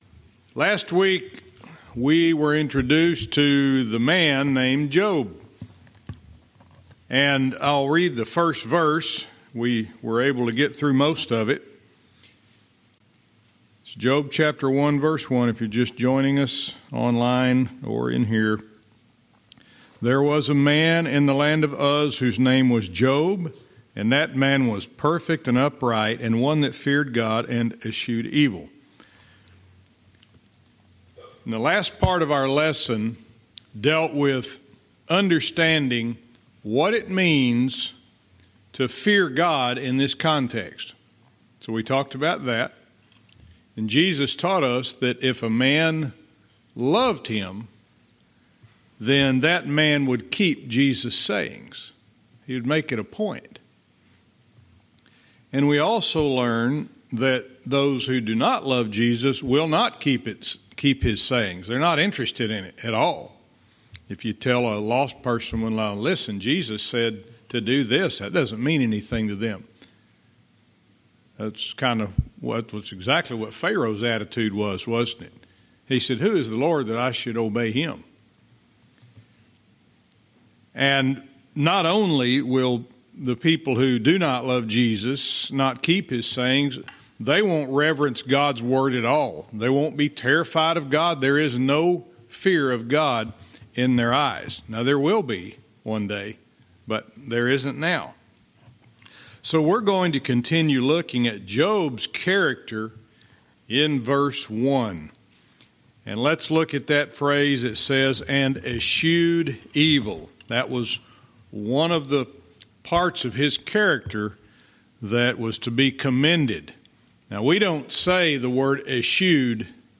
Verse by verse teaching - Job 1:1(cont)-5